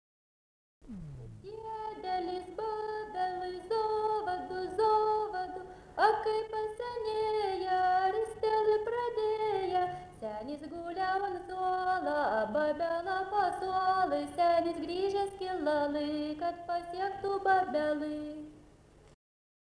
Dalykas, tema daina, šeimos
Atlikimo pubūdis vokalinis